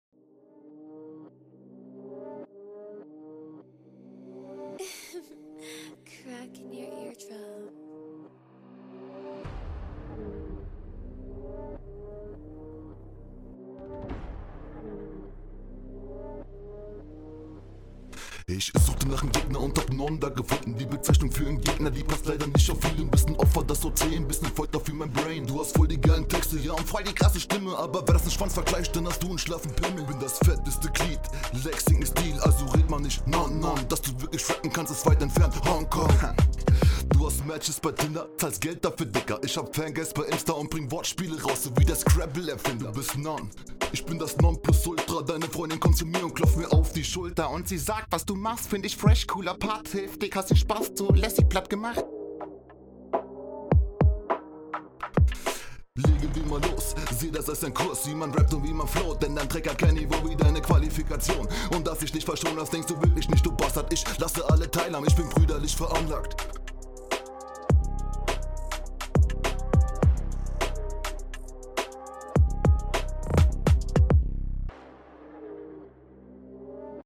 Battle Rap Bunker